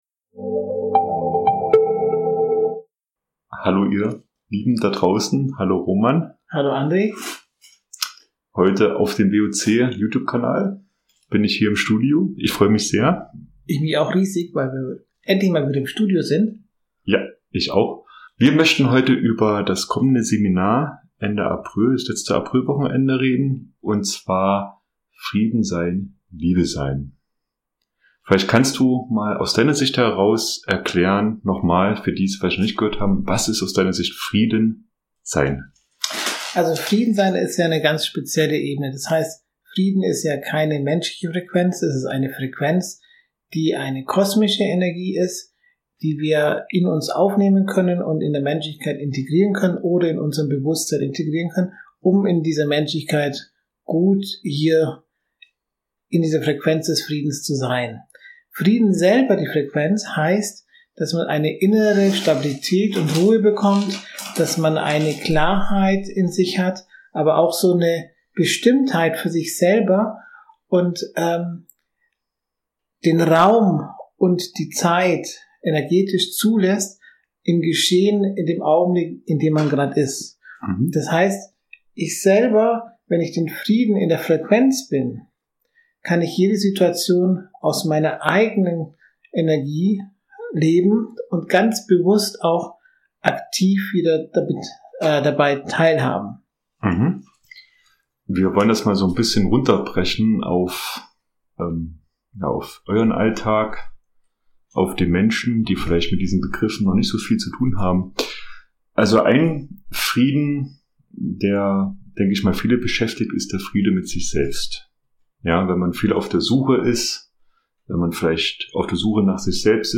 Genau dadurch entsteht ein Gespräch, das nahbar, ehrlich und für viele Menschen direkt greifbar wird.